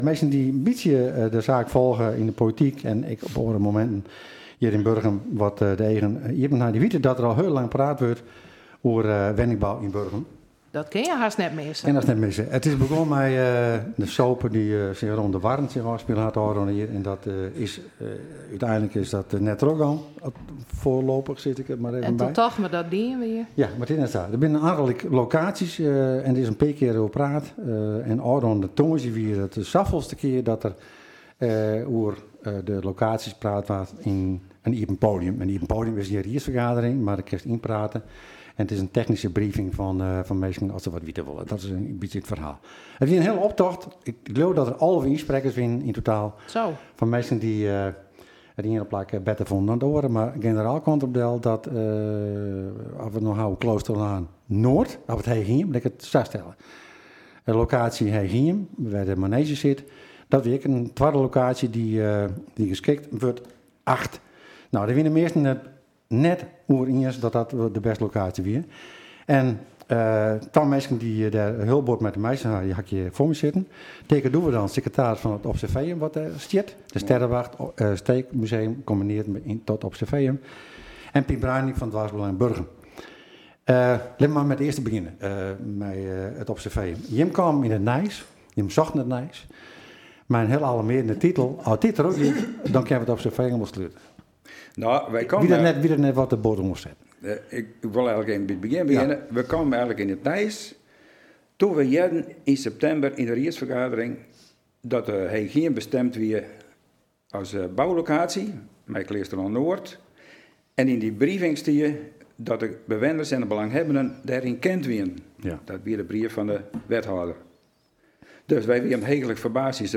Waar het bij de beide studiogasten om draaide was de beoogde locatie Heechhiem.